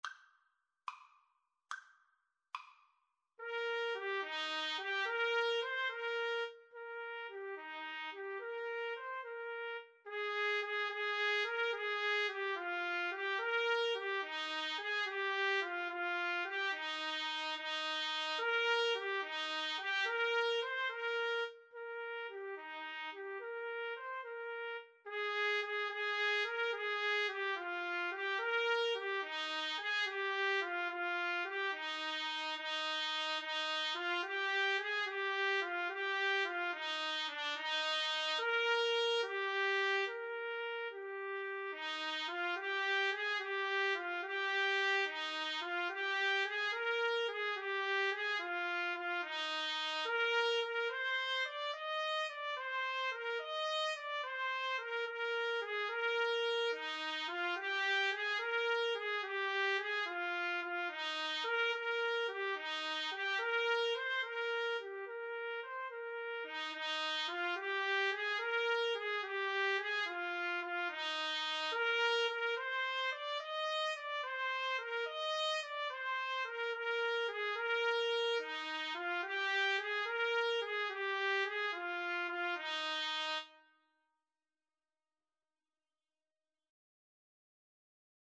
6/8 (View more 6/8 Music)
Maestoso . = c. 72